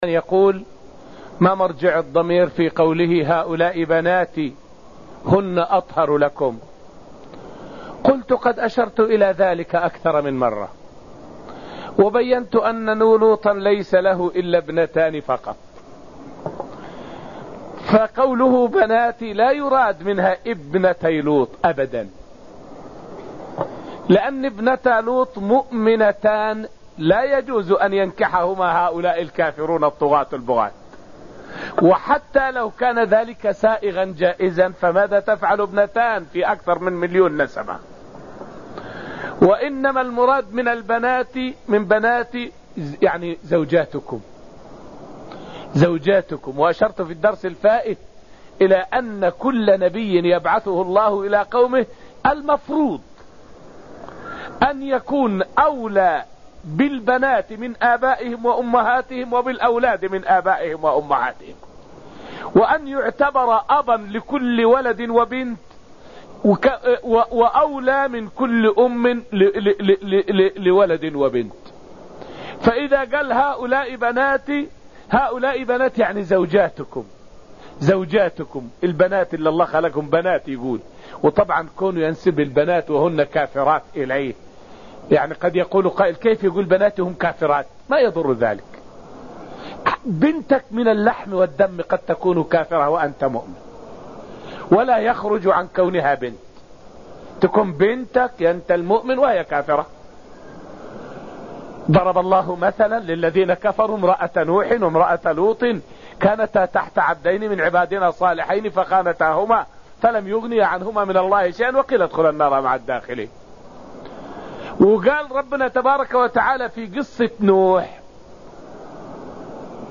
فائدة من الدرس الرابع من دروس تفسير سورة الذاريات والتي ألقيت في المسجد النبوي الشريف حول معنى "بناتي" في قول لوط عليه السلام.